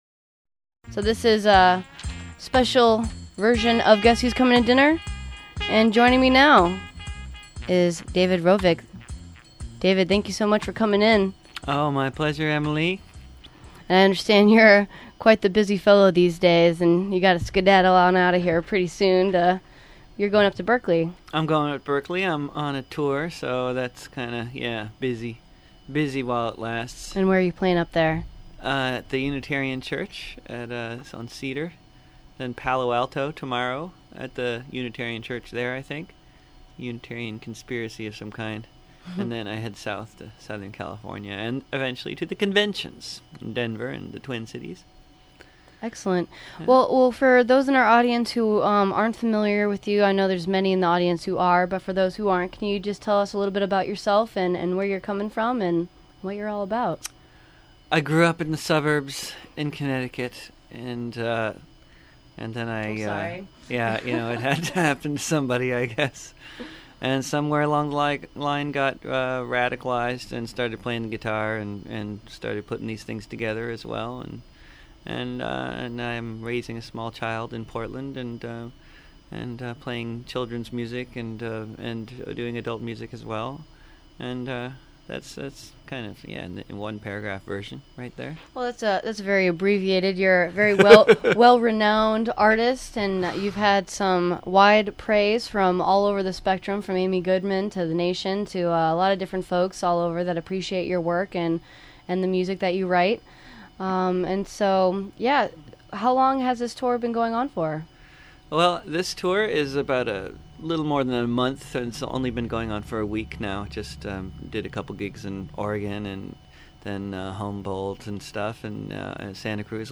In studio Interview and performance